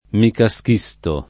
vai all'elenco alfabetico delle voci ingrandisci il carattere 100% rimpicciolisci il carattere stampa invia tramite posta elettronica codividi su Facebook micascisto [ mikašš &S to ] (meno com. micaschisto [ mika S k &S to ]) s. m. (min.)